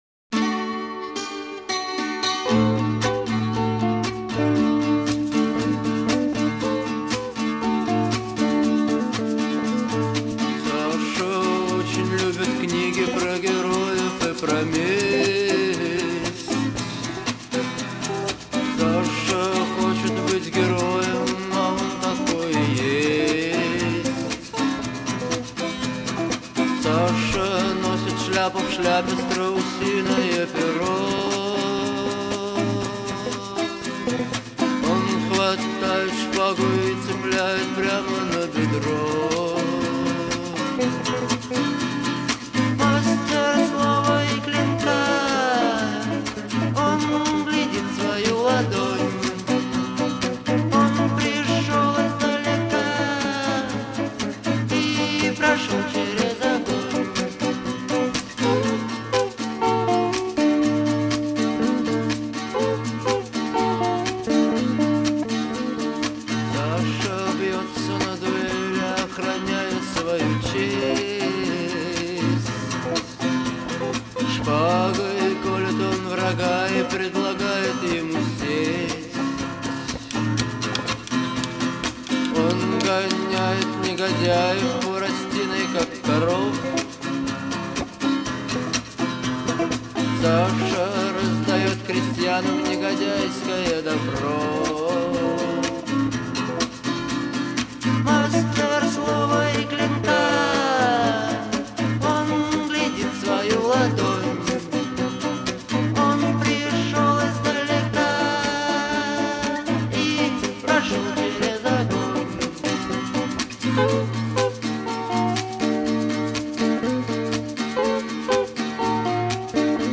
запоминающимися гитарными рифами